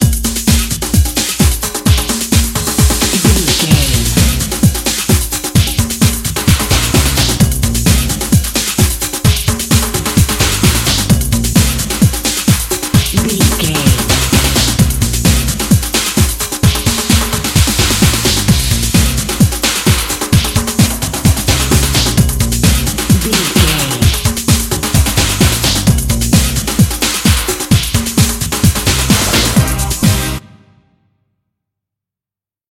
Aeolian/Minor
Fast
drum machine
synthesiser
electric piano
bass guitar
conga
Eurodance